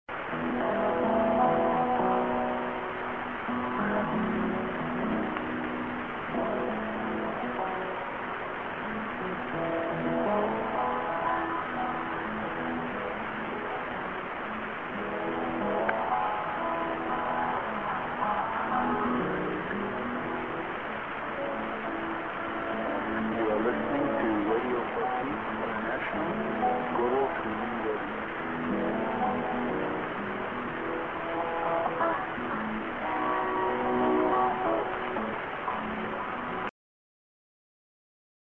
->ID:Youer Listning to RFPI----(man)